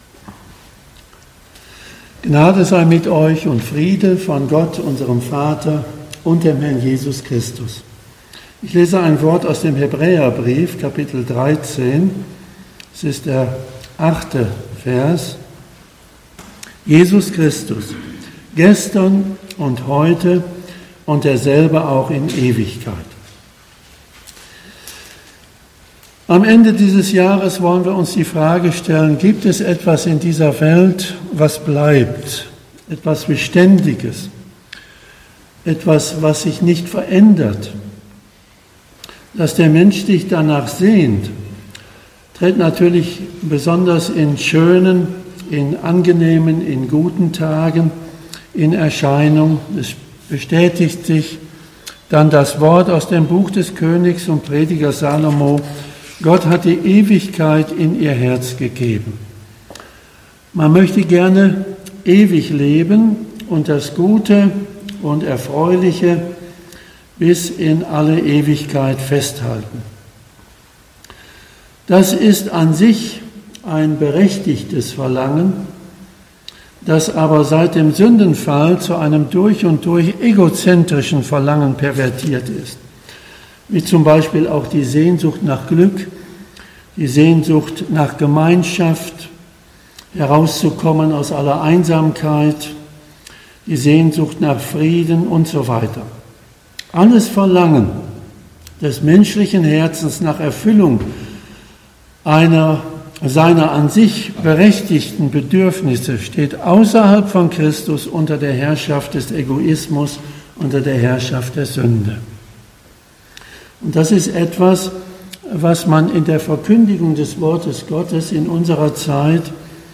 Predigten 2020